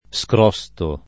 scrostare v.; scrosto [ S kr 0S to ]